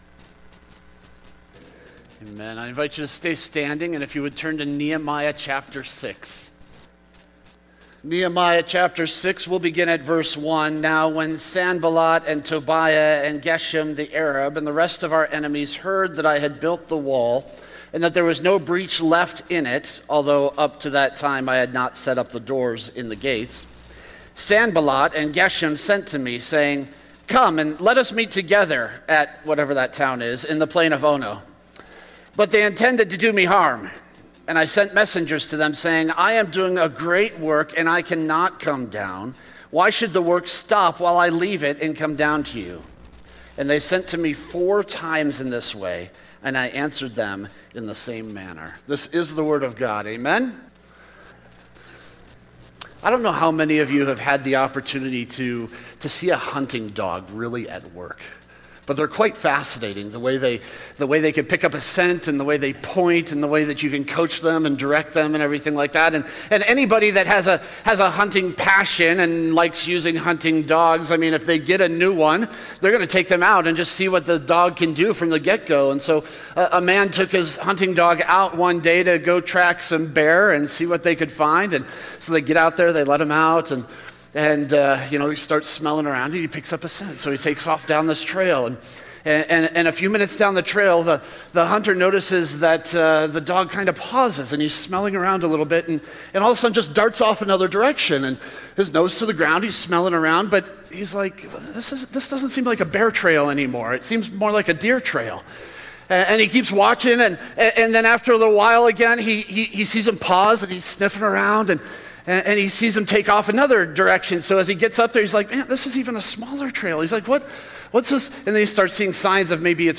Sermons | The Rock of the C&MA